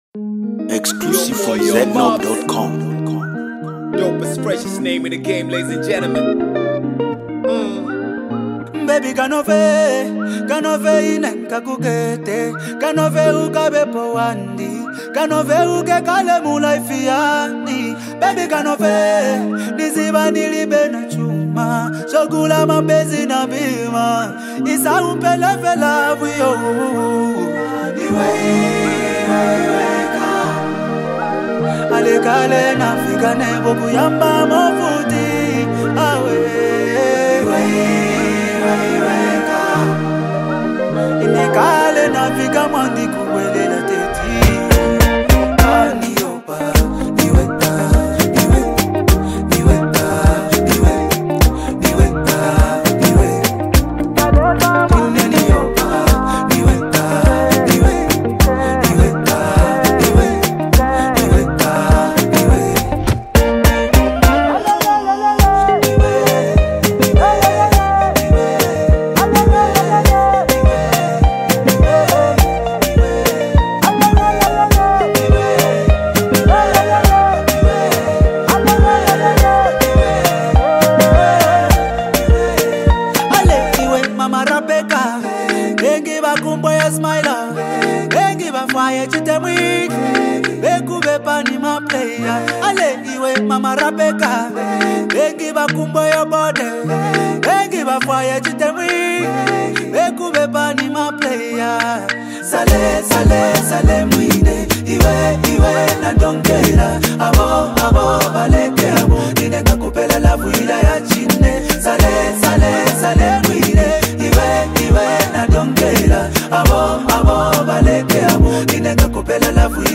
smooth vocals
dynamic rap